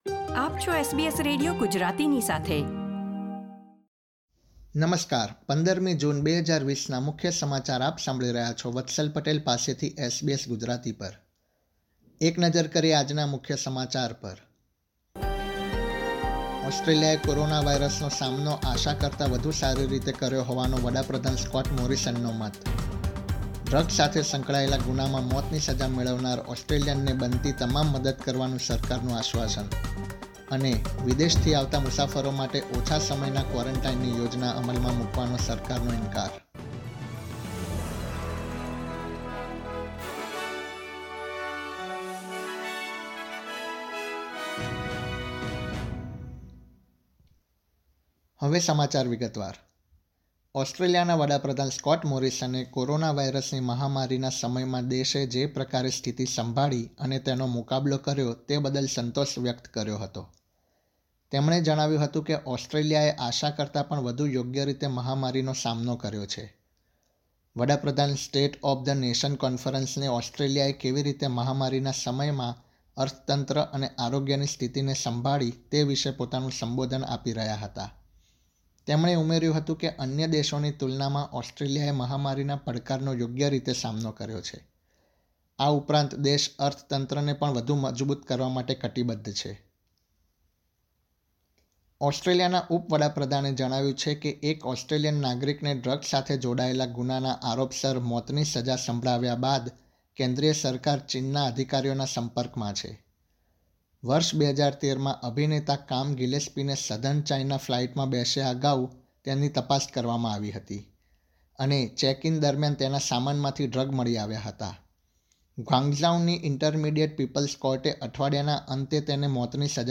SBS Gujarati News Bulletin 15 June 2020